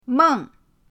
meng4.mp3